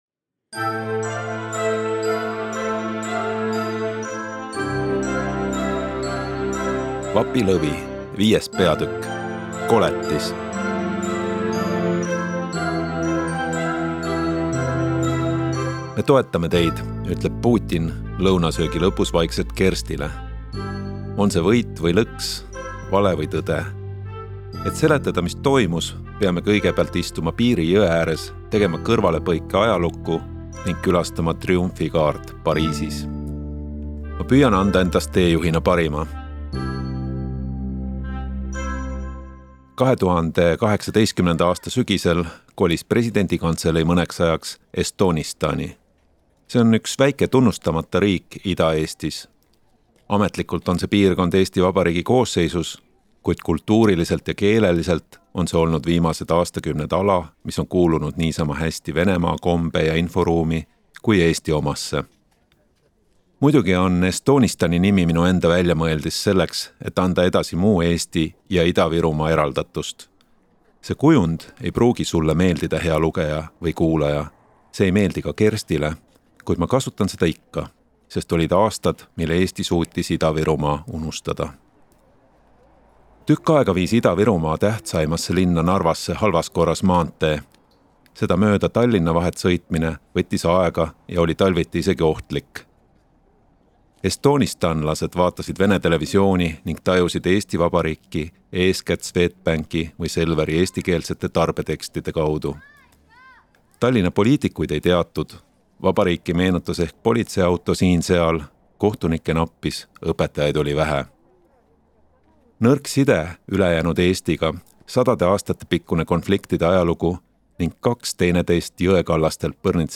Osta kõik peatükid audioraamat e-raamat 11,99 € Telli raamat audioraamat e-raamat paberraamat Järgmine lugu 6. peatükk.